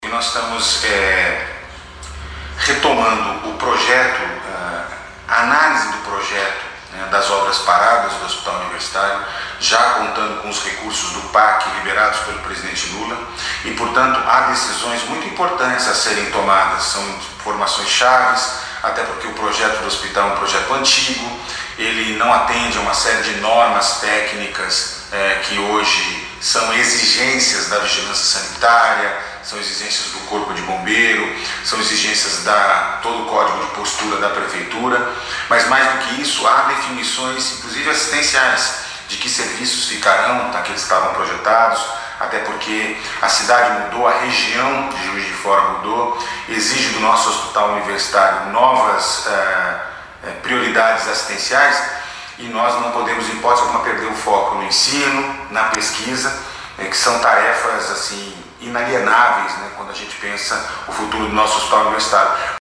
A retomada e conclusão do Novo HU receberam R$ 180 milhões do Programa de Aceleração do Crescimento (PAC) do Governo Federal. Em áudio disponibilizado à Itatiaia, ele afirmou que esse é um momento chave.